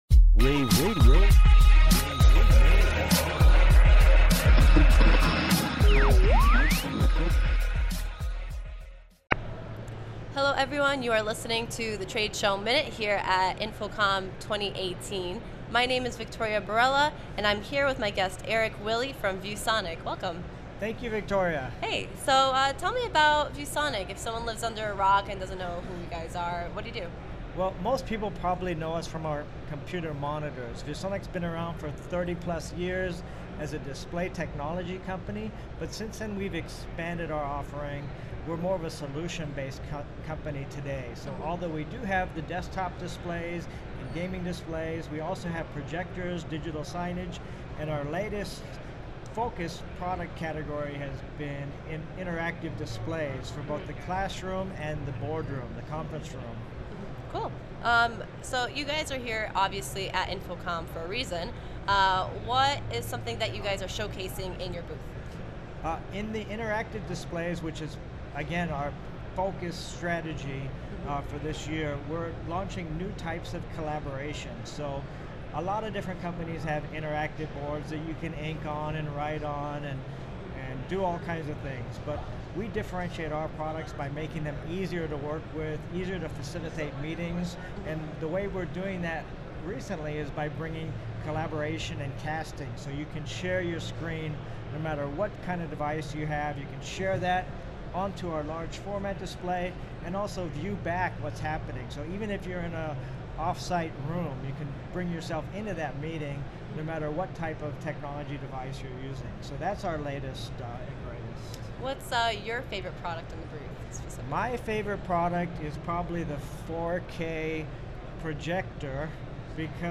InfoComm Radio